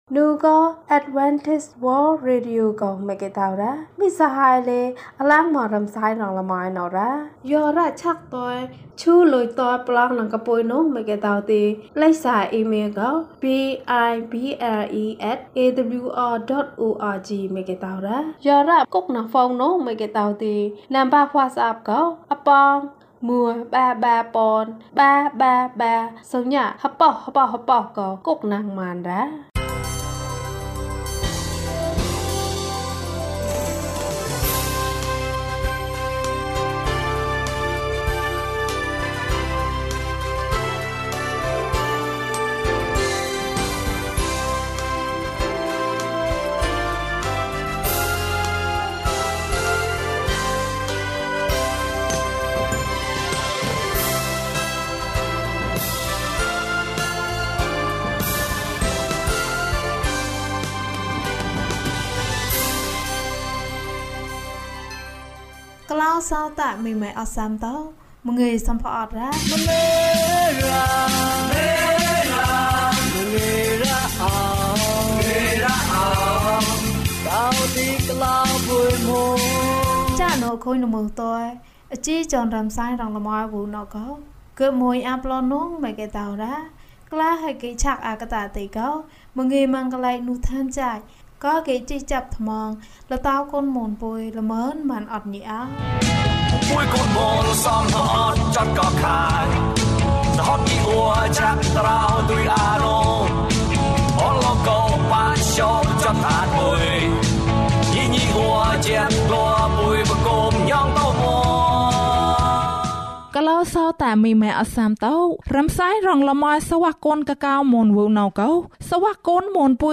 (လ္ပယှအဲဇဲတုဲအာတ်ရာဒနာမိတ်နူဌာန်ကျာ်ညိ)၁ ကျန်းမာခြင်းအကြောင်းအရာ။ ဓမ္မသီချင်း။ တရားဒေသနာ။